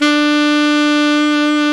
SAX ALTOMP08.wav